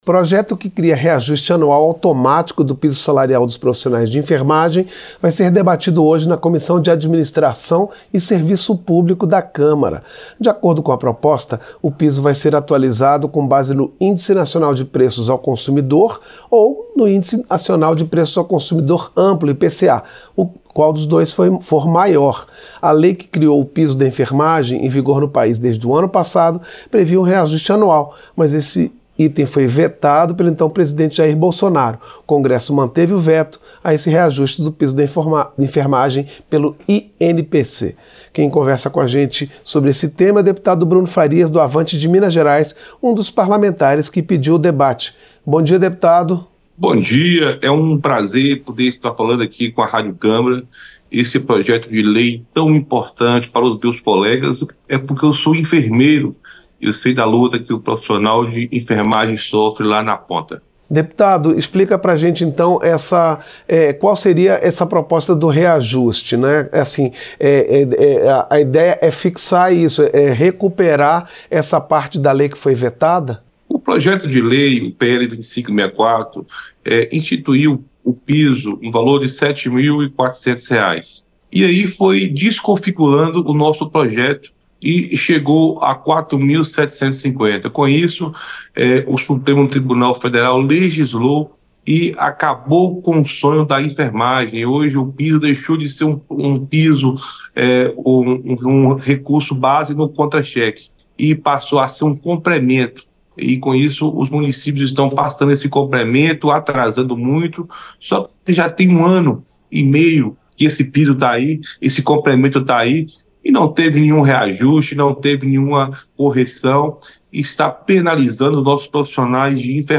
• Entrevista - Dep. Bruno Farias (Avante-MG)
Programa ao vivo com reportagens, entrevistas sobre temas relacionados à Câmara dos Deputados, e o que vai ser destaque durante a semana.